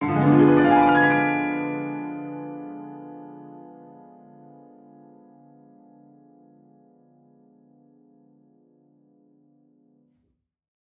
Index of /musicradar/gangster-sting-samples/Chord Hits/Piano
GS_PiChrdTrill-Ddim.wav